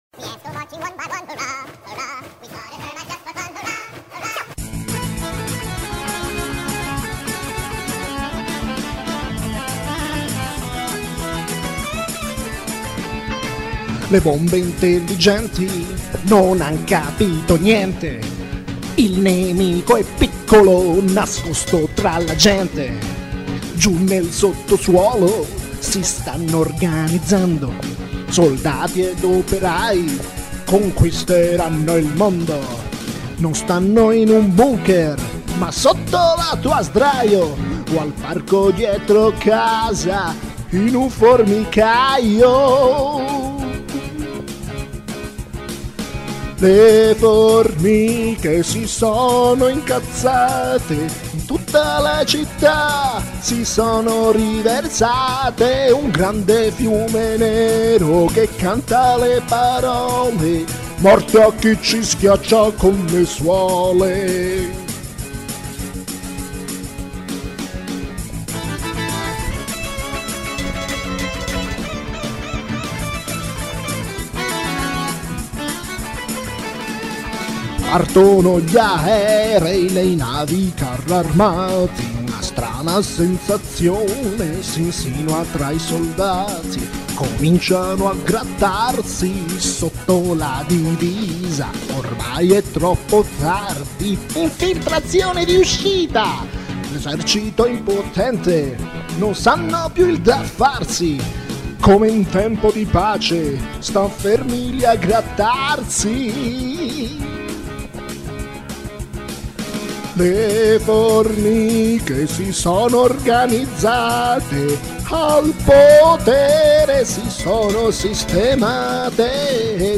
piu' che punk mi e' venuta metal